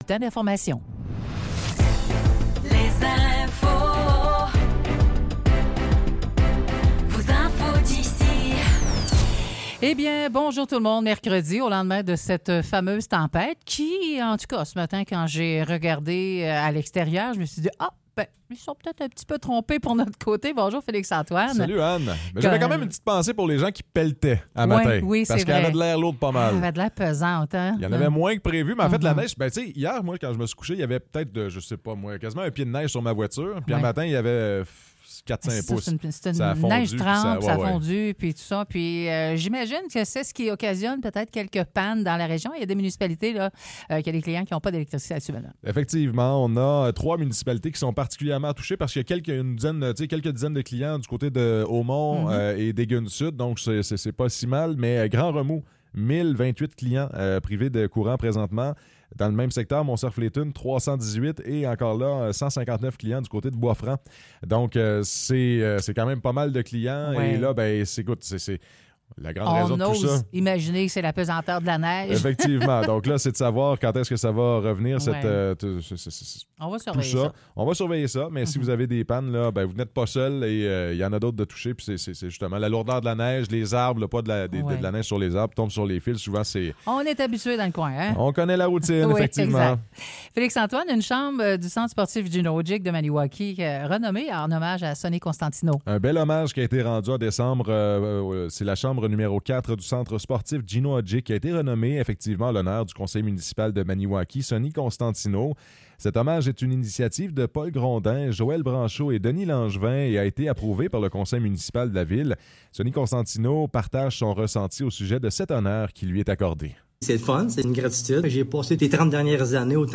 Nouvelles locales - 10 janvier 2024 - 9 h